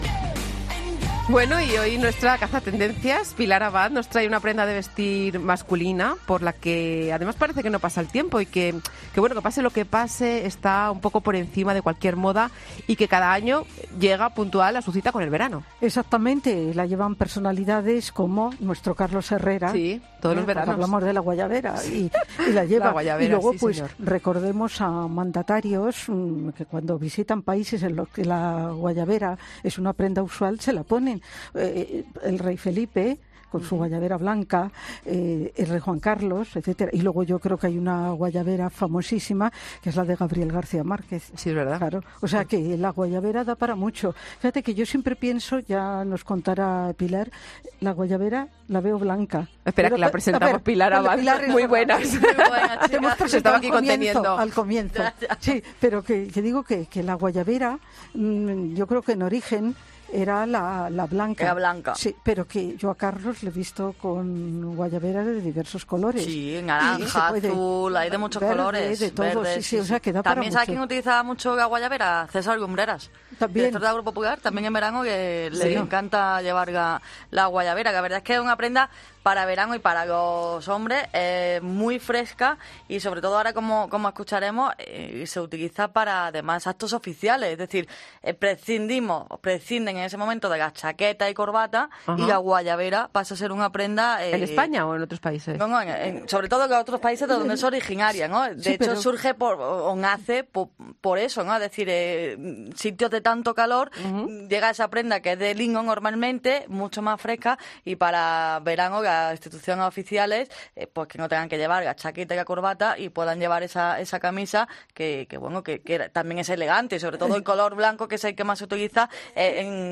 En este PODCAST te contamos el lado más 'cool' de la actualidad con entrevistas a primeras figuras que te cuentan cómo sacarte el máximo partido.